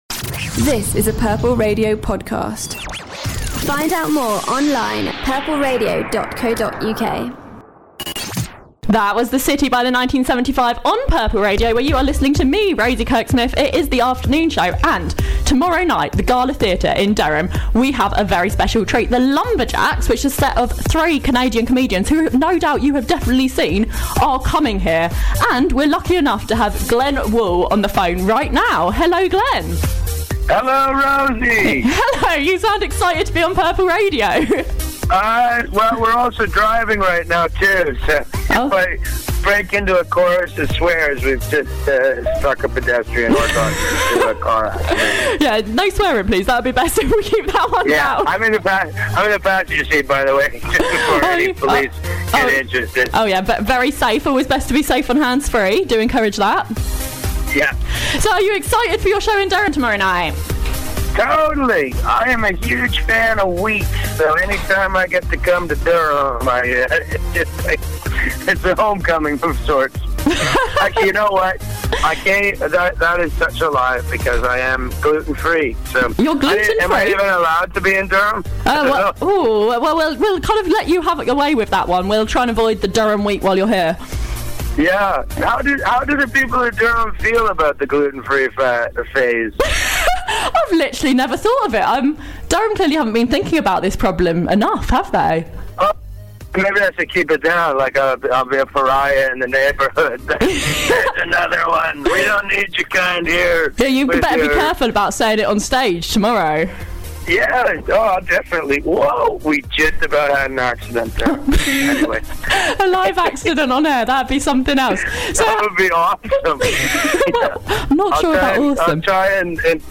Canadian stand-up comedian Glenn Wool chats